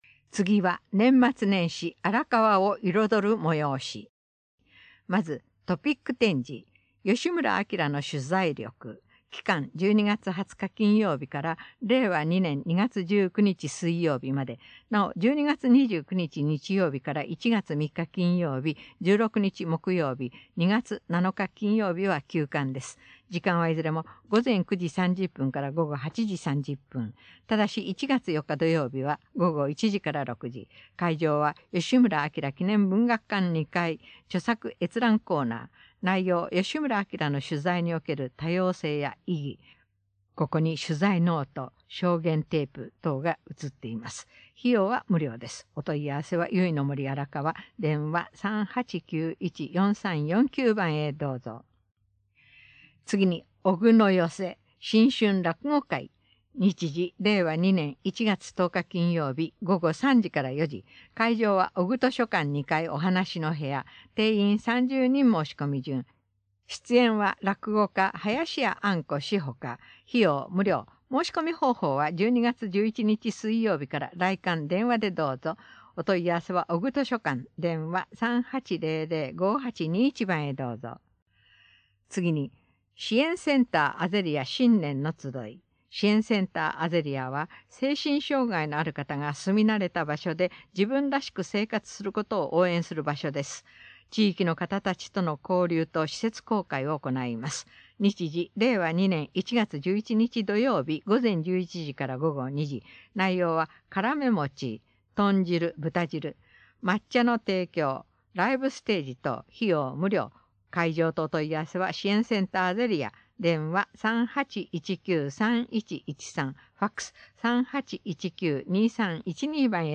トップページ > 広報・報道・広聴 > 声のあらかわ区報 > 2019年 > 12月 > 2019年12月11日号